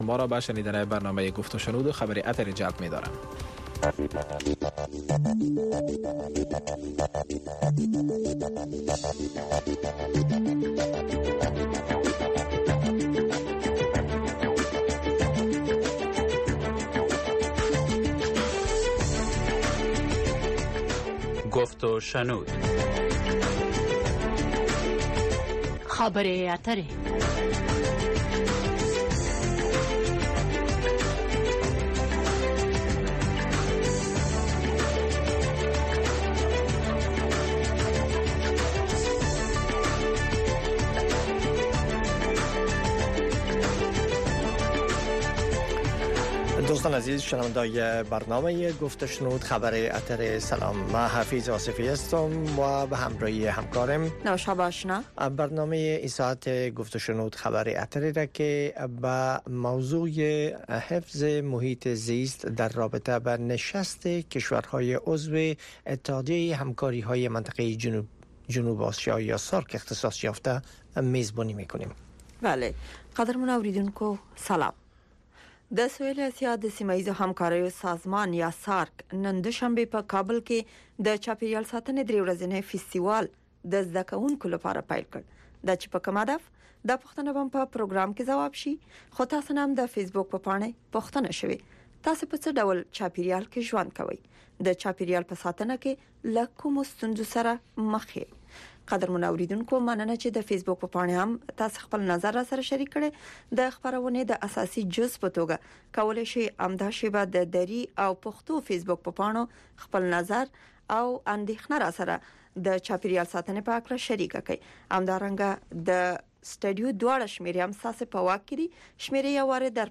گفت و شنود - خبرې اترې، بحث رادیویی در ساعت ۸ شب به وقت افغانستان به زبان های دری و پشتو است. در این برنامه، موضوعات مهم خبری هفته با حضور تحلیلگران و مقام های حکومت افغانستان بحث می شود.